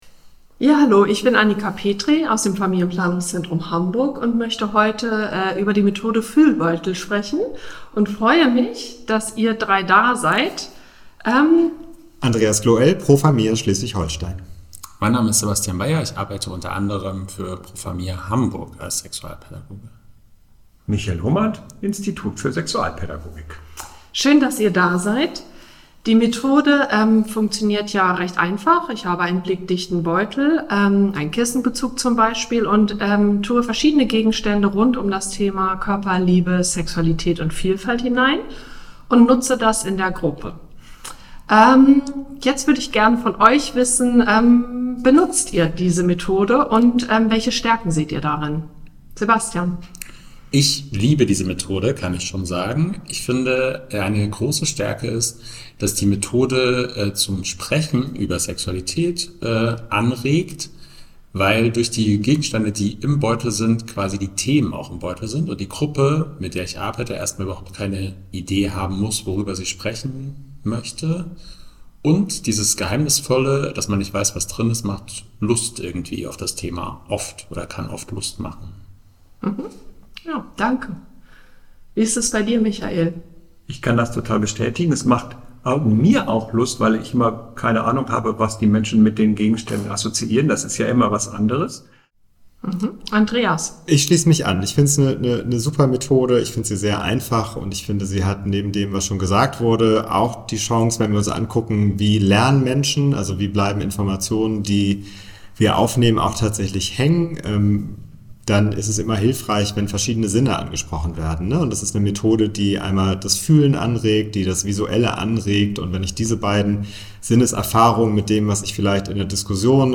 Podcast_Methodendiskussion_Fuehlbeutel.mp3